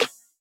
SouthSide Snare (40).wav